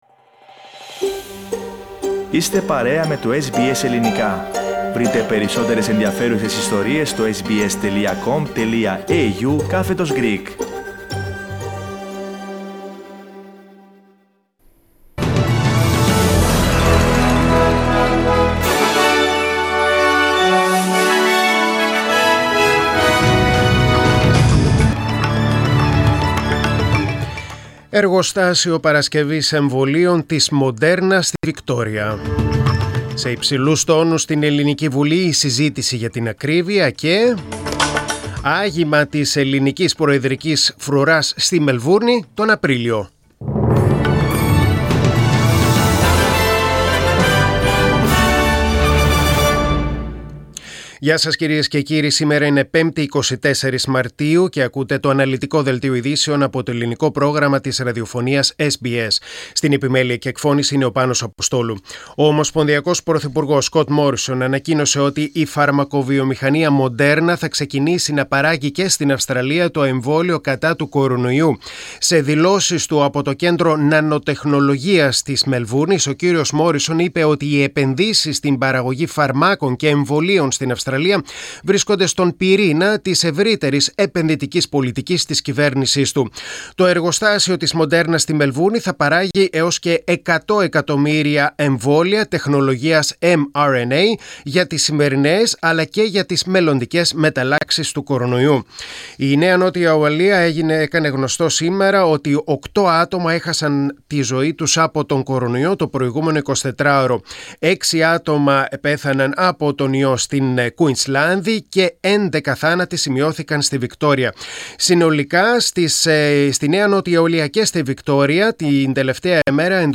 News in Greek: Thursday 24.3.22